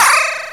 SSPRING.WAV